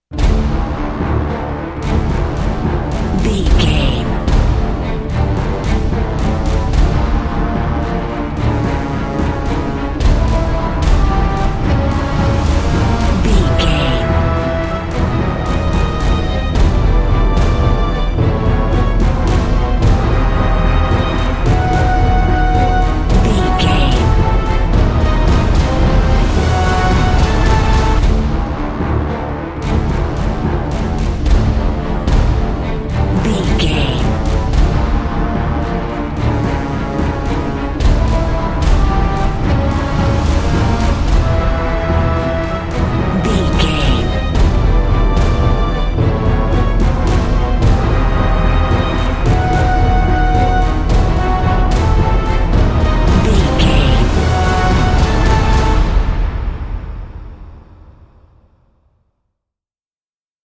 Fast paced
Aeolian/Minor
strings
drums
horns
orchestral hybrid
dubstep
aggressive
energetic
intense
bass
synth effects
wobbles
driving drum beat
epic